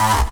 tekTTE63028acid-A.wav